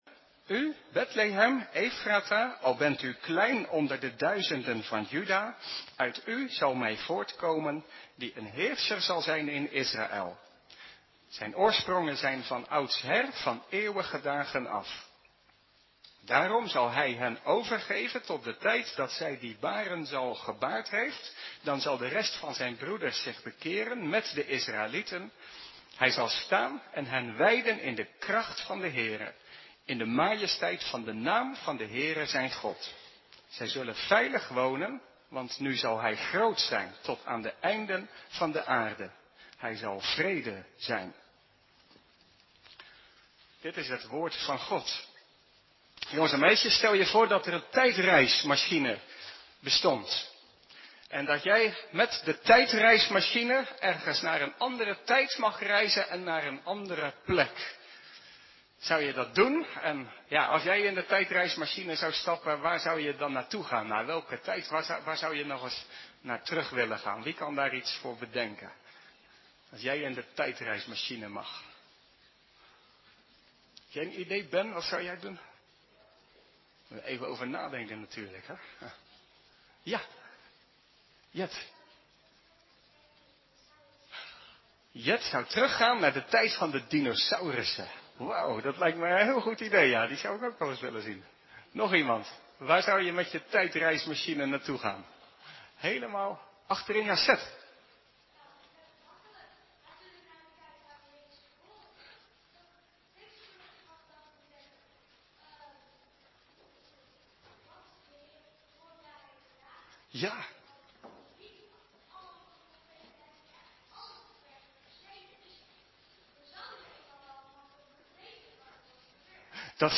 Adventspreek 1: Er komt een nieuwe leider (Micha 5)
adventspreek-1-er-komt-een-nieuwe-leider-micha-5.mp3